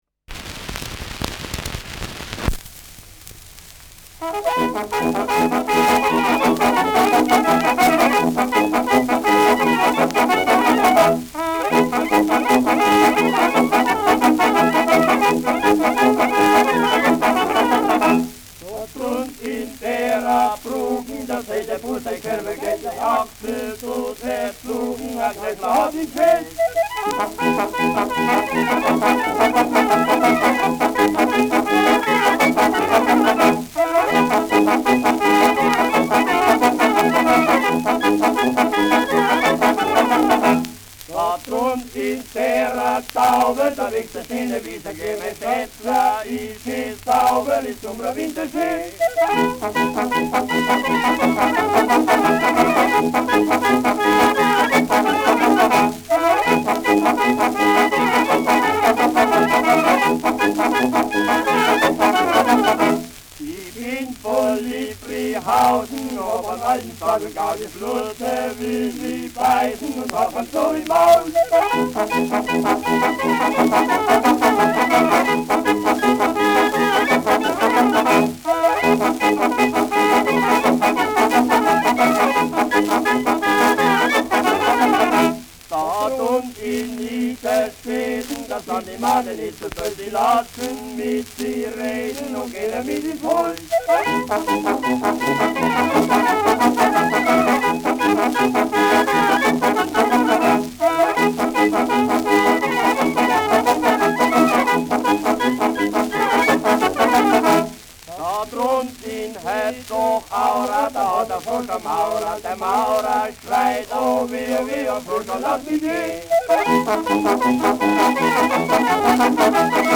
Schellackplatte
Tonrille: Kratzer 3 Uhr Leicht
Der gesungene Dreher orientiert sich an der Art, wie in Franken bis heute gesungene Tanzverse, Vierzeiler oder Kerwalieder begleitet werden. Auf die ohne Musik vorgetragenen Verse spielt die Kapelle die gehörte Melodie nach, wobei sie diese nach ihren Fertigkeiten ausziert und variiert. Für die Aufnahme übernimmt sie hier auch den Part der Sänger (sonst sind das die Kirchweihburschen).